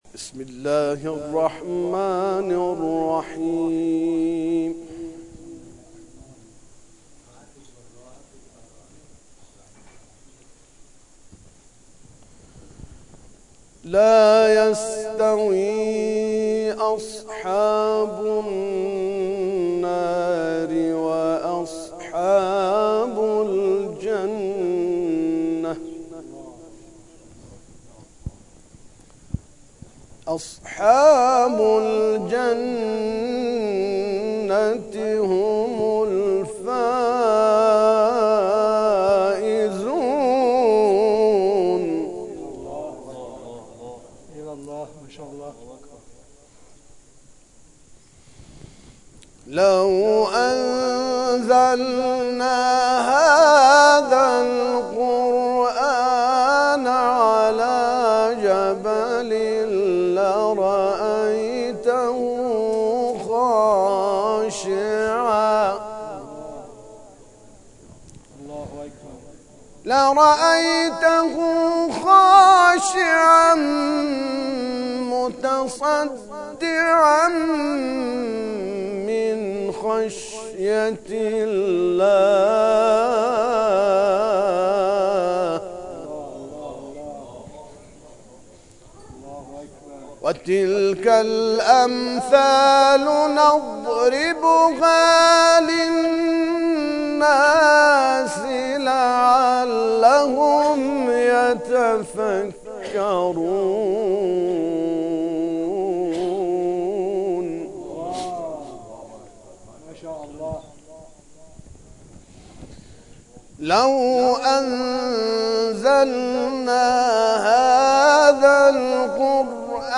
سپس آیات انتهایی سوره مبارکه حشر را تلاوت کرد که در ادامه ارائه می‌شود.
تلاوت